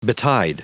Prononciation du mot betide en anglais (fichier audio)
Prononciation du mot : betide